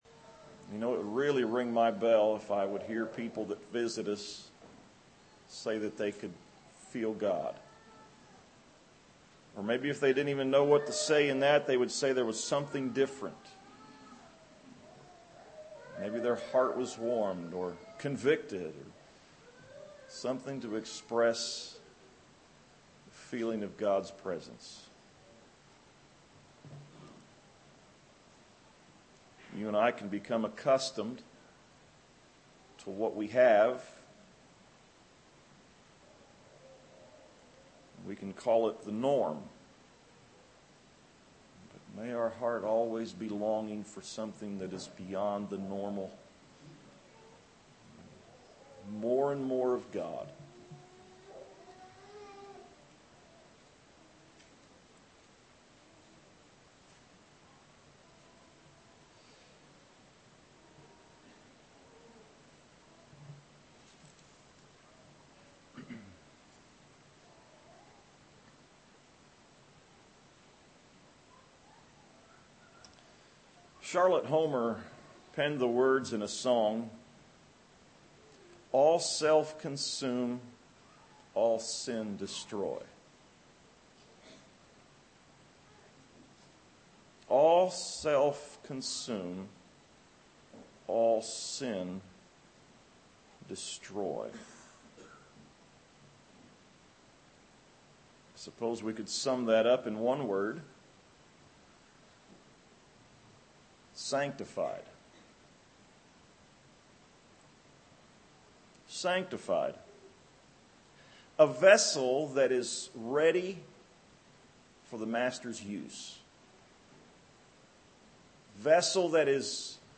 Save Audio A message